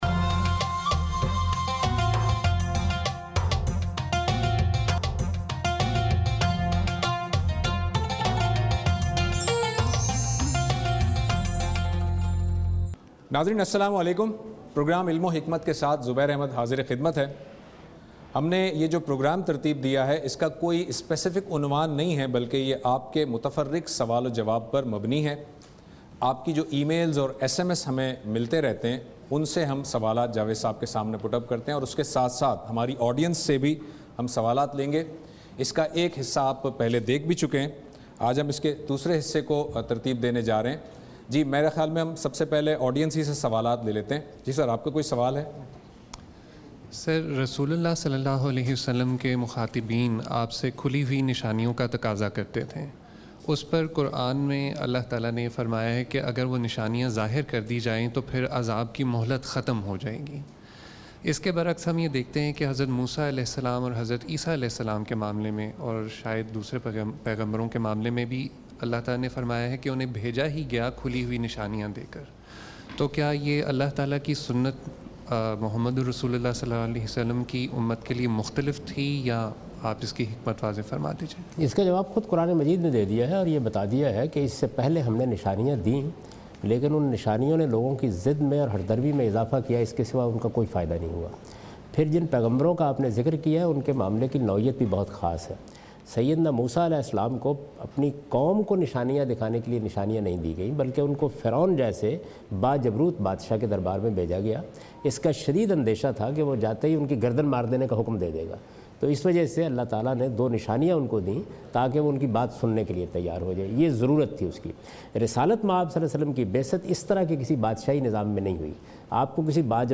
In this program Javed Ahmad Ghamidi answers the questions of audience.